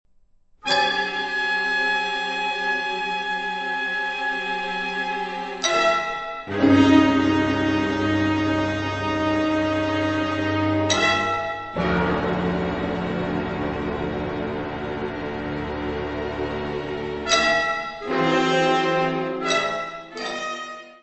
: stereo; 12 cm + folheto
piano
trompa
xilorimba
glockenspiel
Área:  Música Clássica